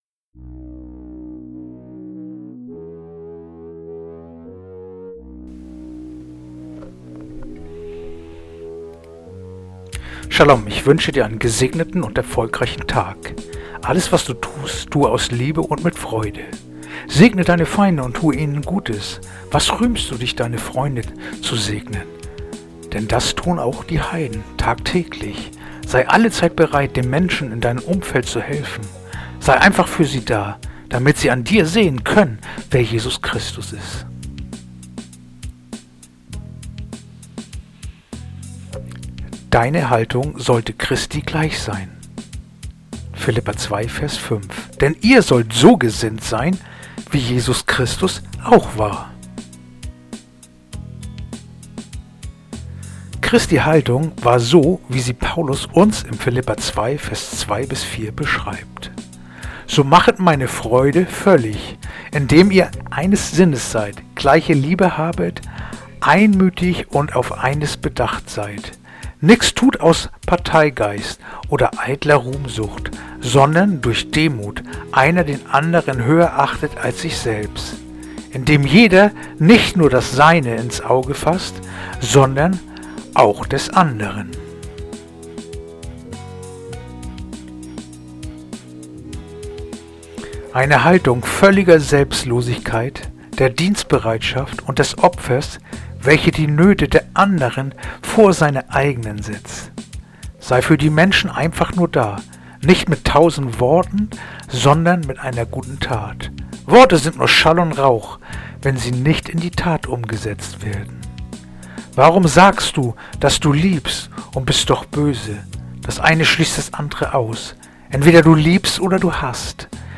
heutige akustische Andacht
Andacht-vom-08.-November-Philipper-2-5.mp3